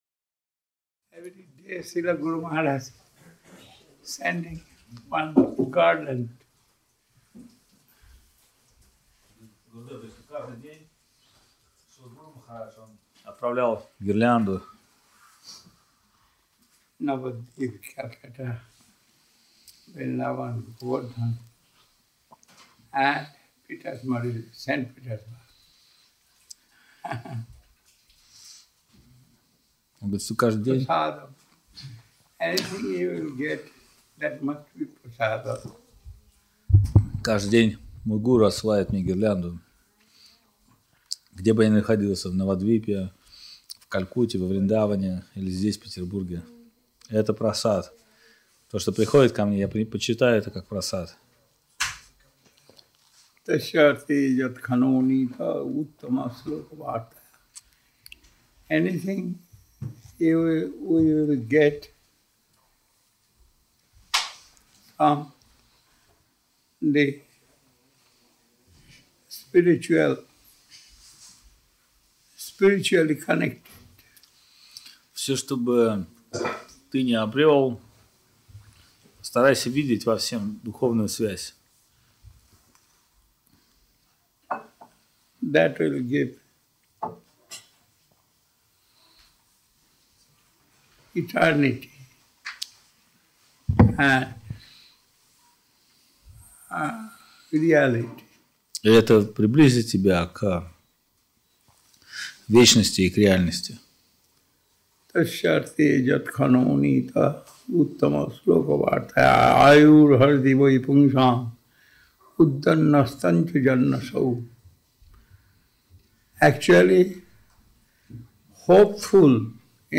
Даршаны 2006
Place: Sri Chaitanya Saraswat Math Saint-Petersburg